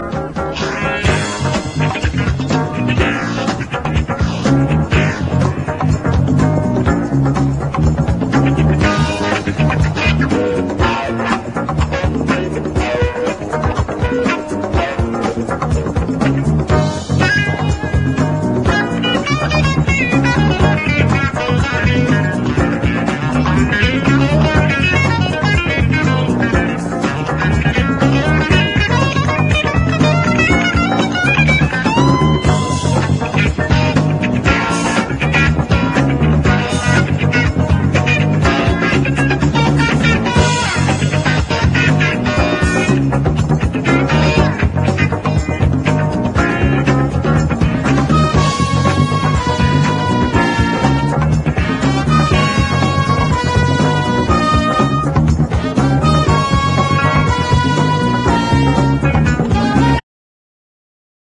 JAZZ / DANCEFLOOR / JAZZ FUNK / FUSION / RARE GROOVE / PIANO